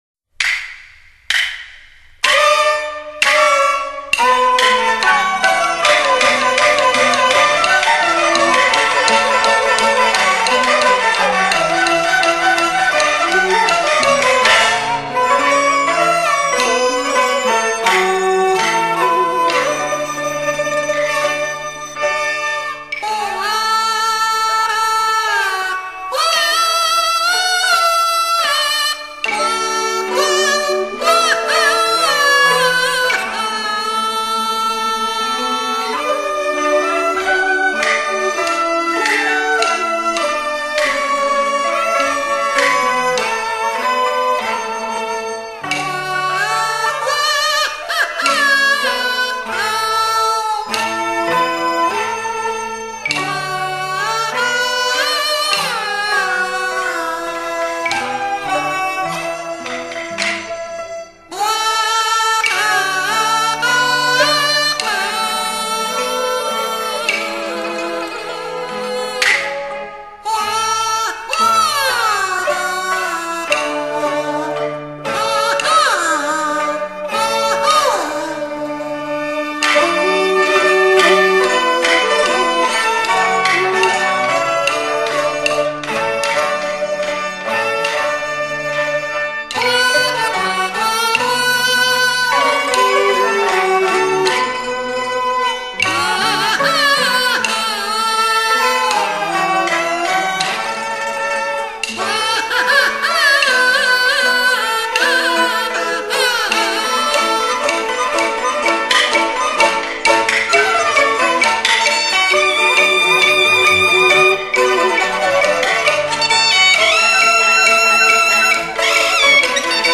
旋律采用河南豫剧的曲调，用唢呐模拟人声演奏，俗称“卡戏”，歌唱性很强。乐曲表现了浓郁的生活气息和强烈的地域色彩。
唢呐独奏